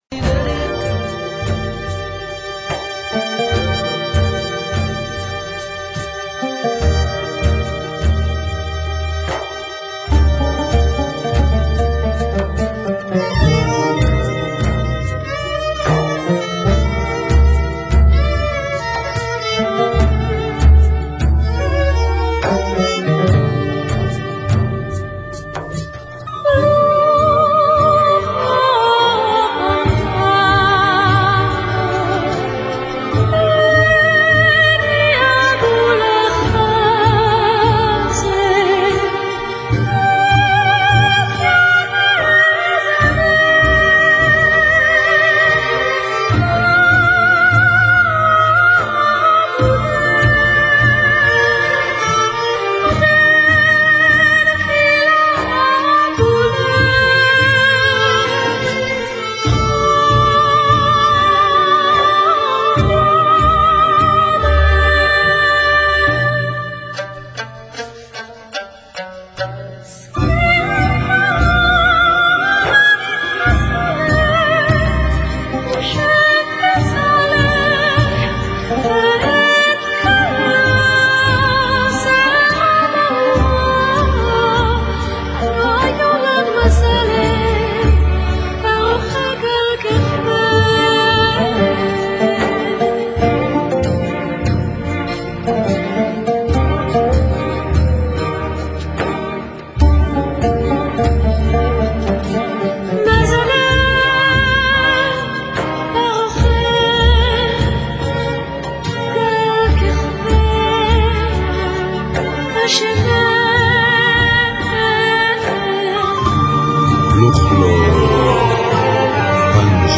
괜찮은 음악들/국내외 연주곡